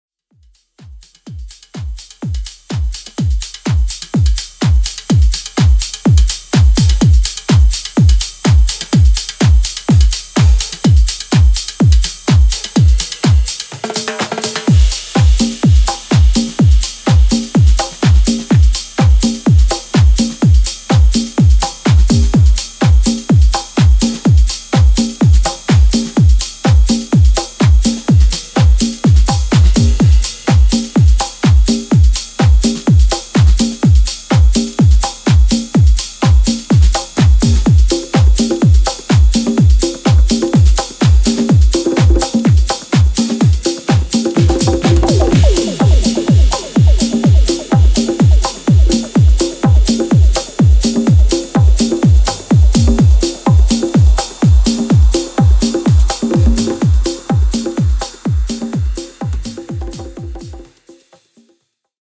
のリズムを抽出したA-2が、使い勝手抜群のツール・トラックとなっているのでお聴き逃しなく！